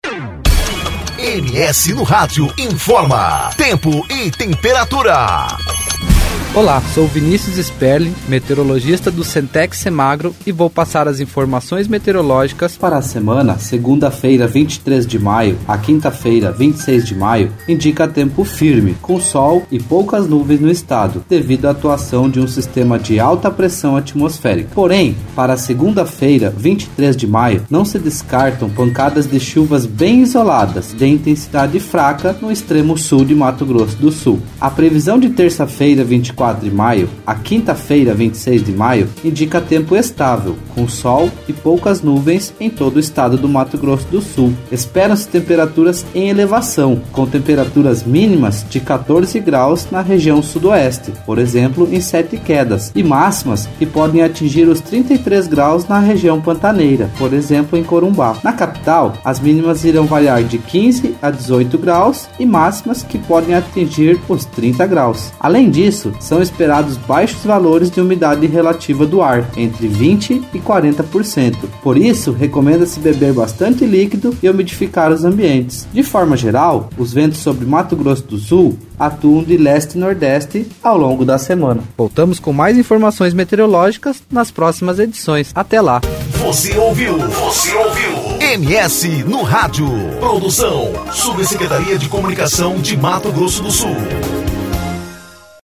Previsão do Tempo: Semana será marcada por baixos índices de umidade e temperaturas em elevação